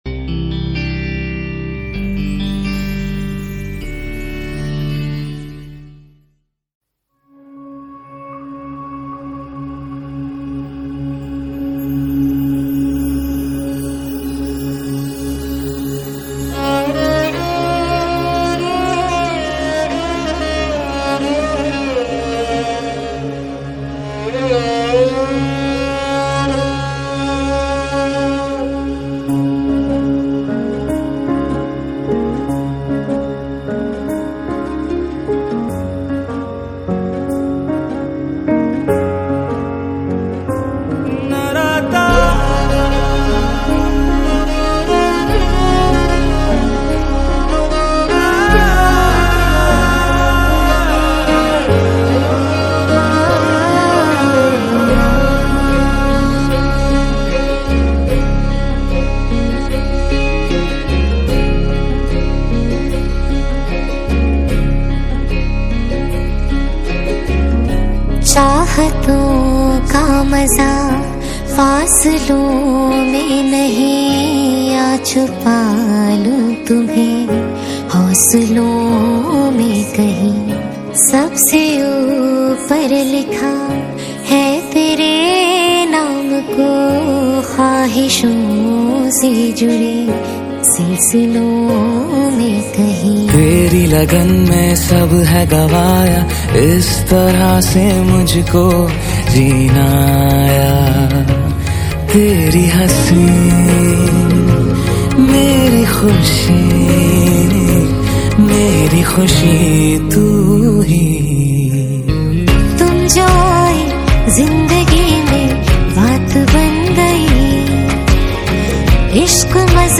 Hindi Mixtape Songs